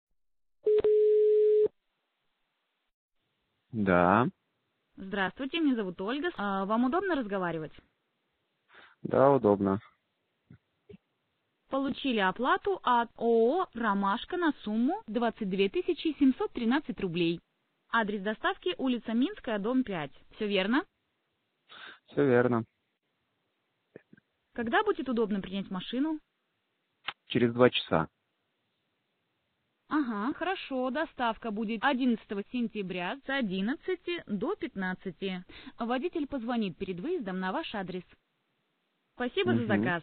Голосовой робот звонит клиенту, чтобы подтвердить адрес доставки и уточнить удобное для клиента время доставки. Робот корректно распознает свободно сформулированные временные интервалы, например, «через три часа», «послезавтра до часу» или «в пятницу после полудня» и вносит информацию в соответствующую базу данных. В ходе разговора робот также способен подтвердить поступление оплаты от клиента.